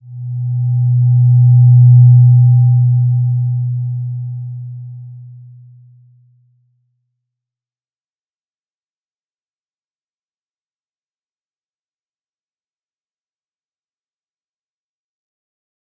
Slow-Distant-Chime-B2-p.wav